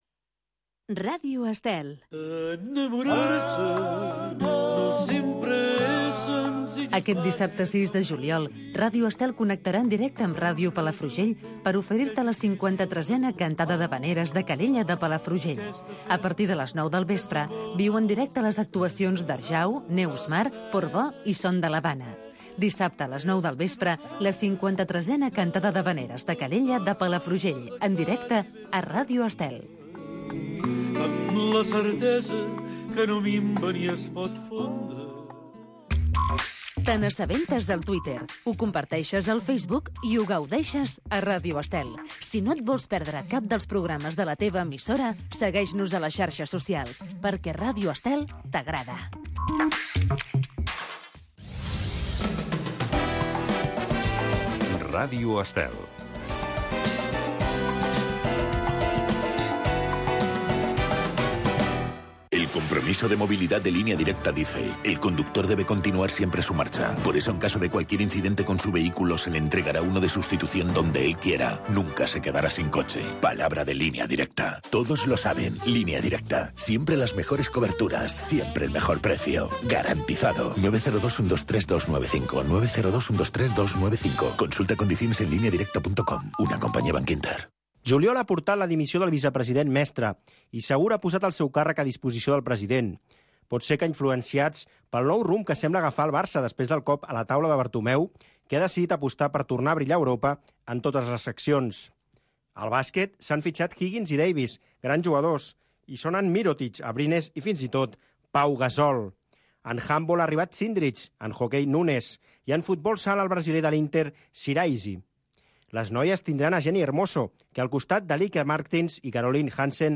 El 10 del Barça. Programa de l'actualitat del Fútbol Club Barcelona per a tots els culers del món. Amb entrevistes, tertúlia.